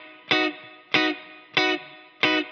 DD_TeleChop_95-Bmaj.wav